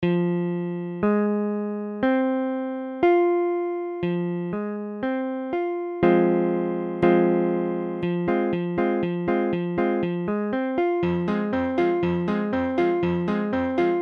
Tablature Fm.abcFm : accord de Fa mineur
Mesure : 4/4
Tempo : 1/4=60
A la guitare, on réalise souvent les accords en plaçant la tierce à l'octave.
Fa mineur Barré I (fa case 1 do case 3 fa case 3 doigt 4 la bémol case 1 do case 1 fa case 1)